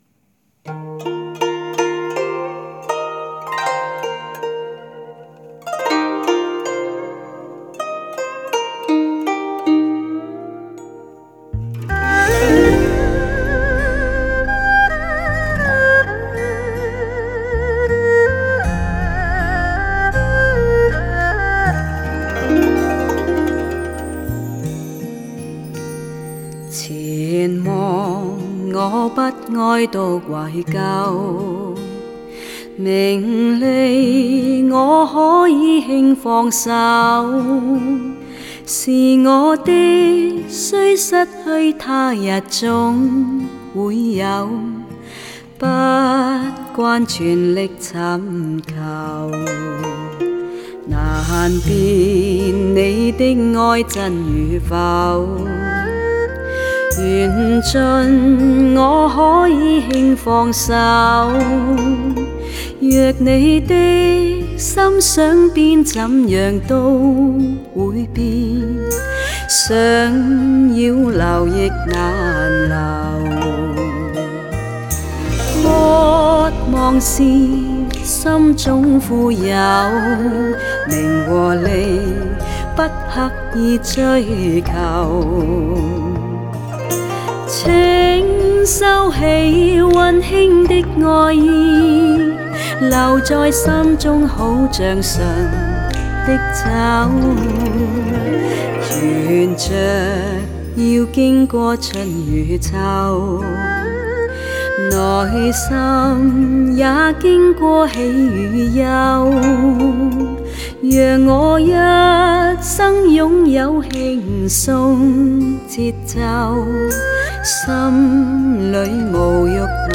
怀旧情怀，发烧韵味。
粤语专辑越来越少，流行化的发烧市场上，显得独有特色，
由资深女歌手演唱的十数首经典名曲，
温暖、恬然、醇厚等美声特质，
让全碟散发出浓浓的凄美、含蓄与深情，